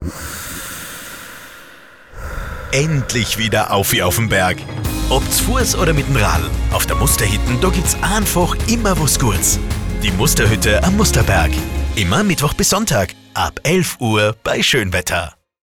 Links hört ihr die Stimme unseres Sprechers, rechts die KI-generierte Variante – ein direkter Vergleich für Ausdruck, Natürlichkeit und Emotionalität.
Radiowerbespot 02
Radiospot "Dialekt"